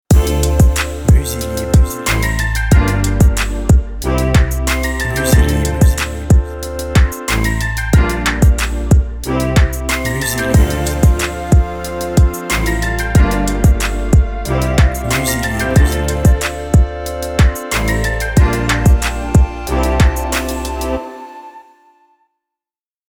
Jingle fun, léger et positif sur un rythme hiphop!
BPM Moyen